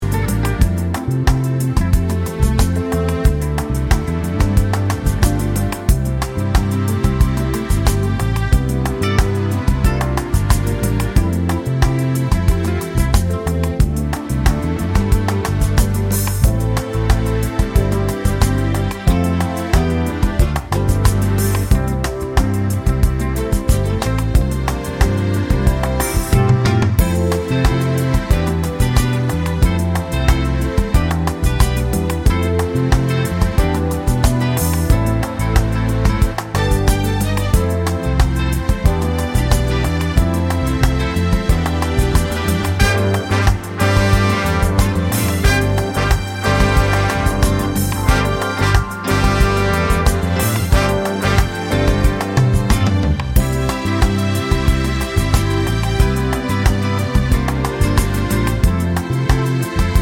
no Backing Vocals Soul / Motown 3:59 Buy £1.50